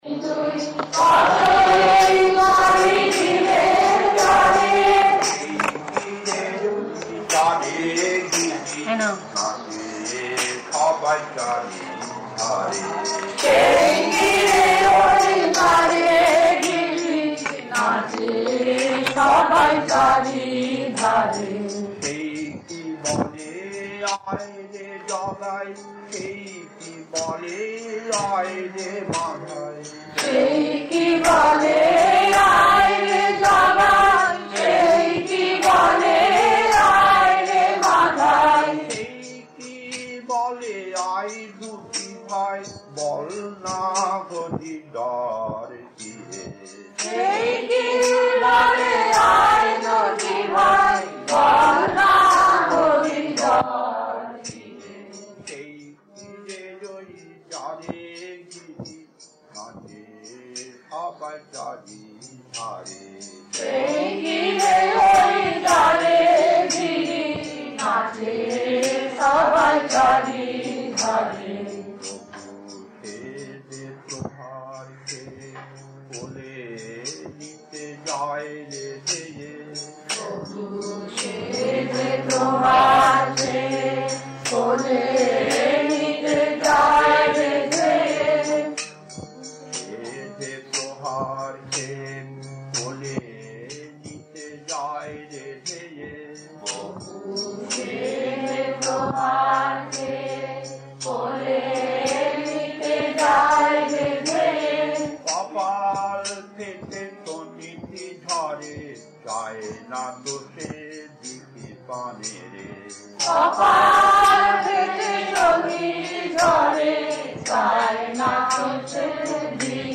Kirtan
Delhi